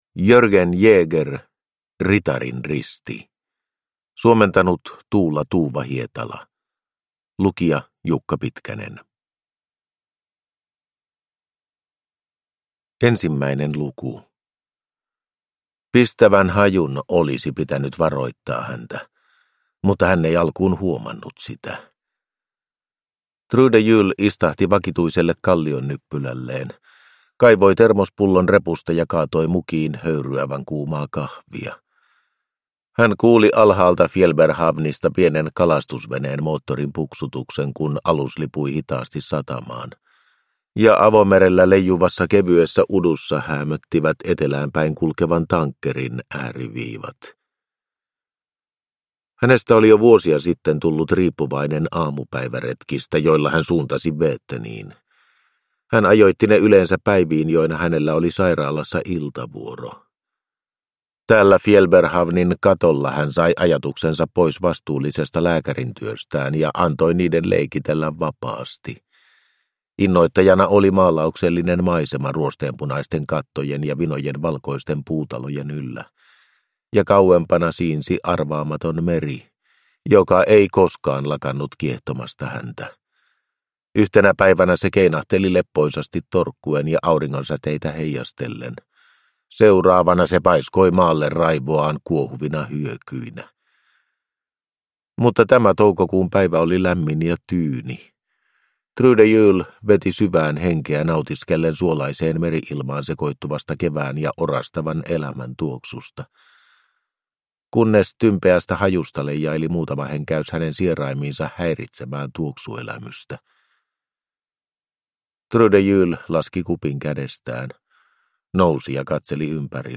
Ritarin risti (ljudbok) av Jørgen Jæger